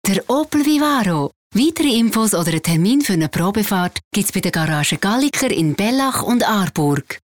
Werbung Schweizerdeutsch (AG)
Sprecherin mit breitem Einsatzspektrum.